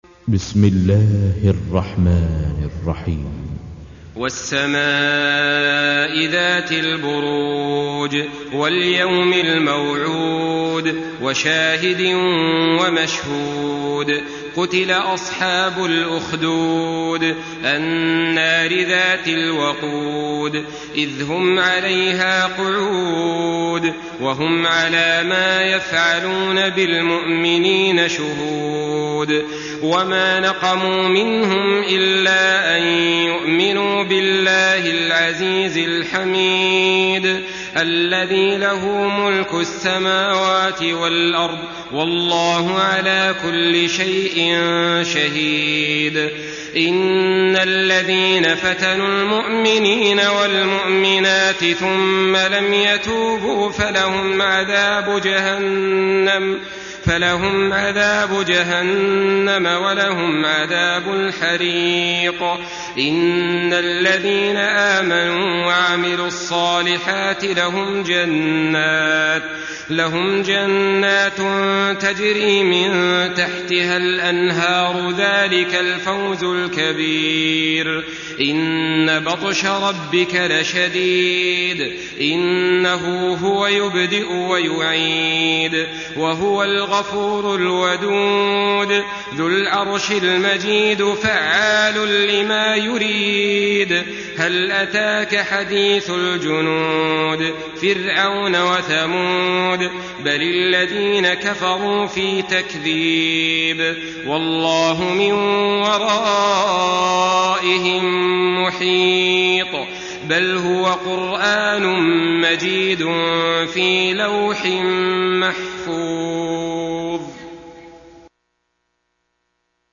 Surah Al-Buruj MP3 by Saleh Al-Talib in Hafs An Asim narration.
Murattal Hafs An Asim